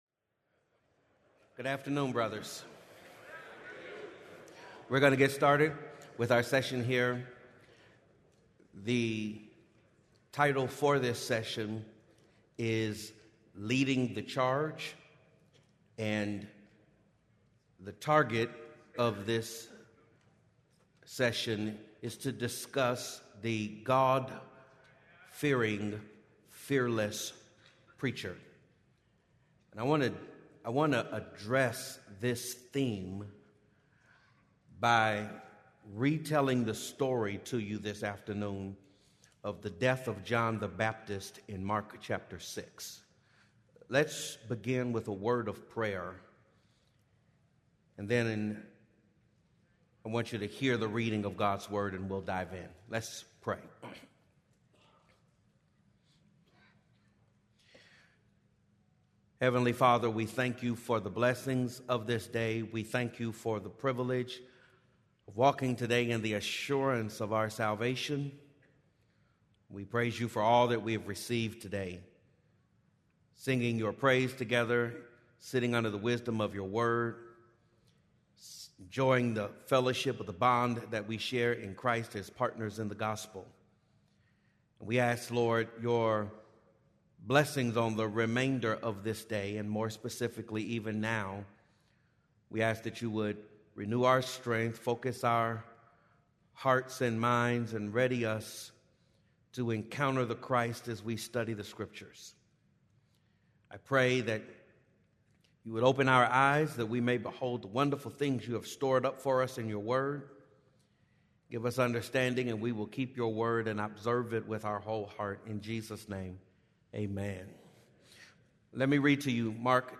Shepherds Conference Sermon Podcast - Leading the Charge | Free Listening on Podbean App